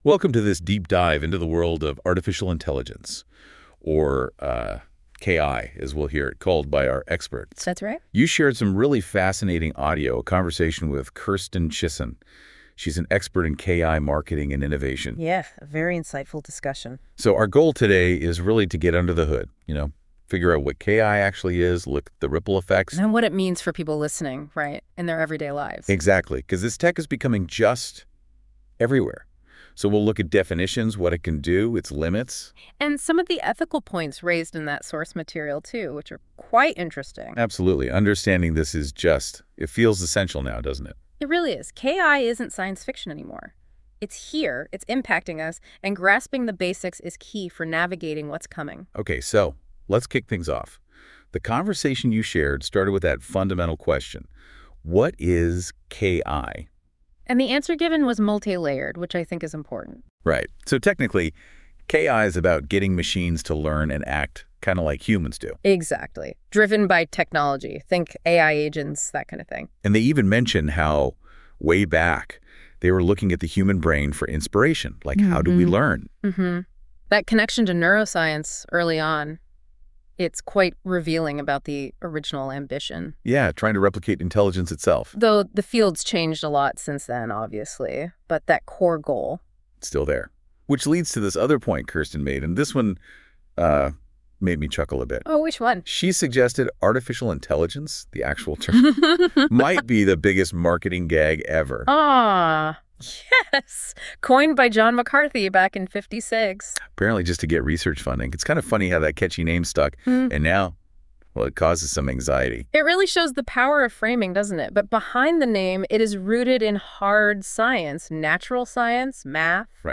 After receiving numerous inquiries about offering our podcast in English, we’ve decided to provide an AI-generated audio summary of each episode moving forward.